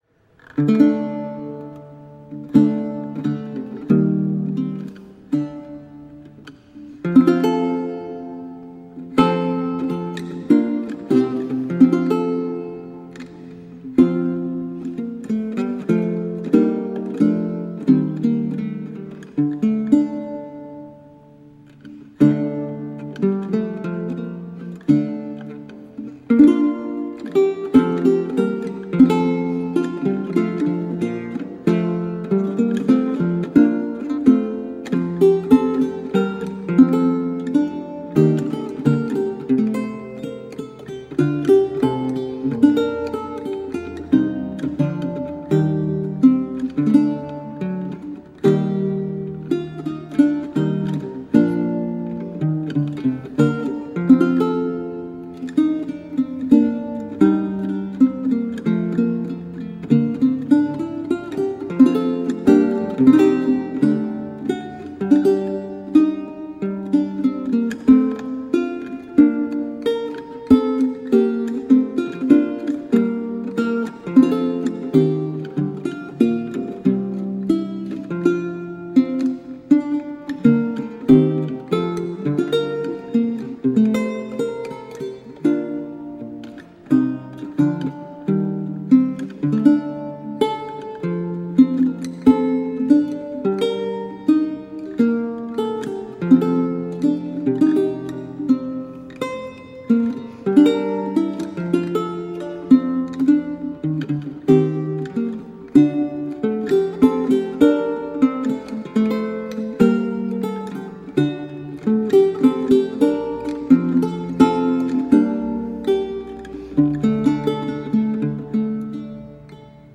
Vihuela, renaissance and baroque lute.
Classical, Renaissance, Instrumental, Lute